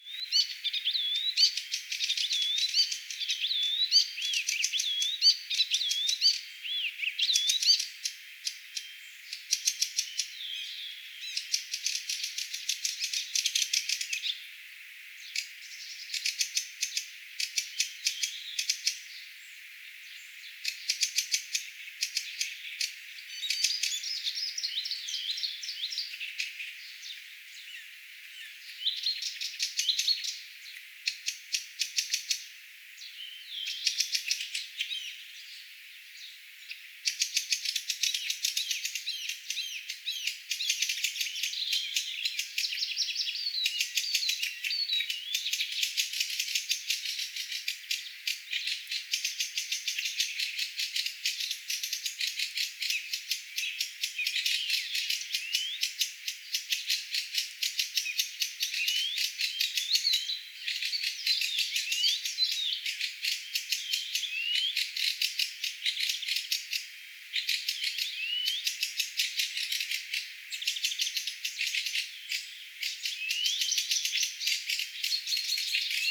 vähän kultarinnan laulua
kultarinta_laulaa.mp3